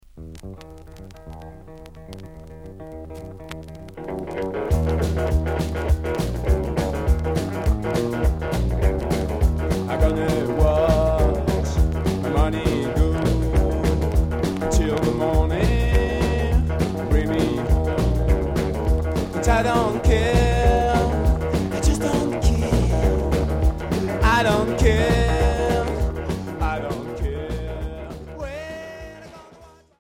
Psychobilly Unique 45t retour à l'accueil